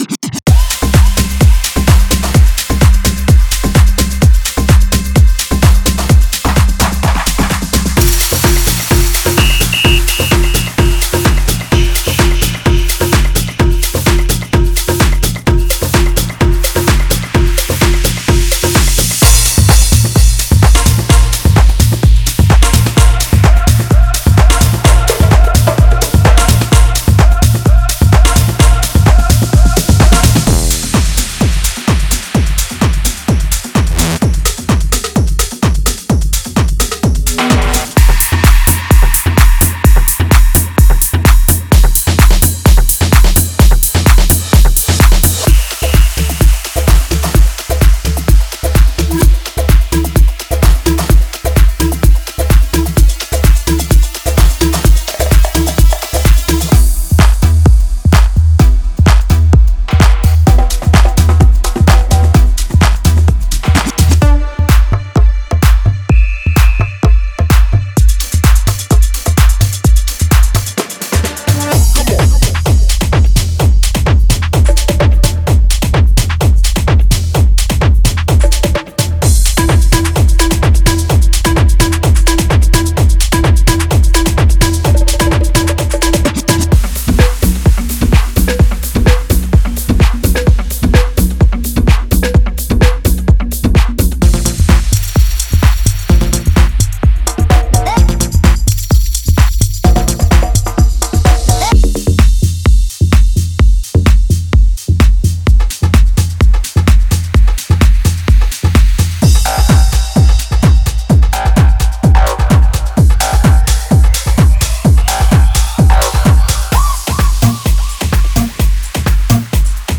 プロのプロデューサーの方々に向けて設計されており、エネルギッシュでフェスティバル向きのサウンドを提供します。
デモサウンドはコチラ↓
Genre:Tech House